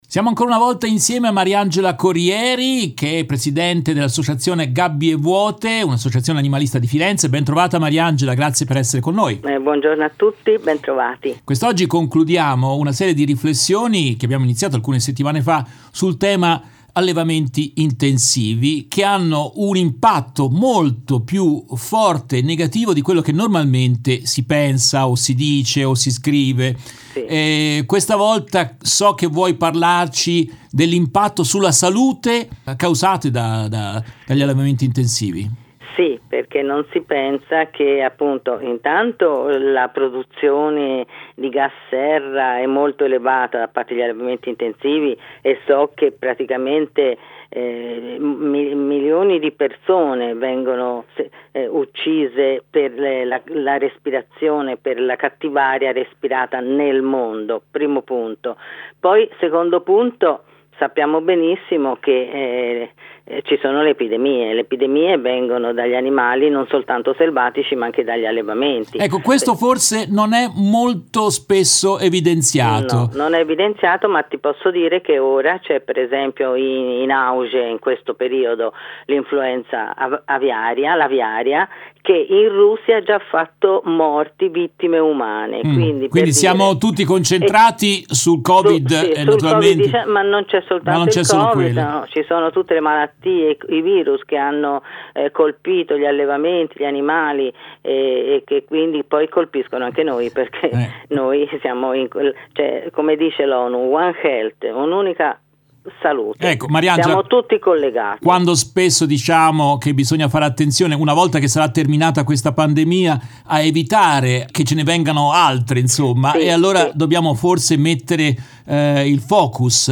In questa intervista tratta dalla diretta RVS del 19 novembre 2021